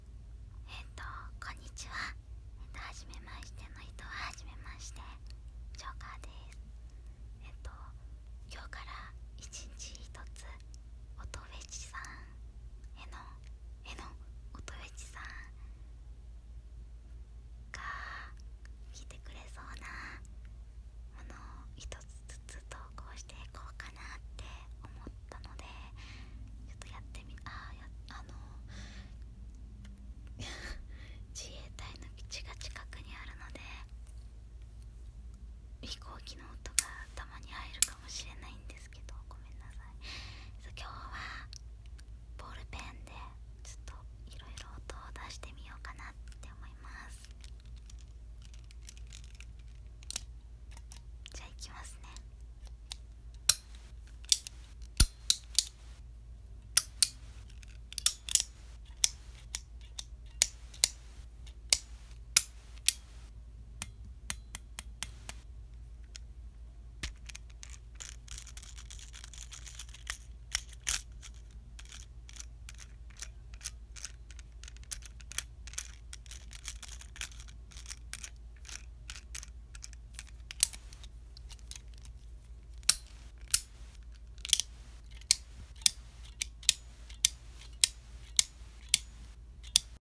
音フェチ★ボールペン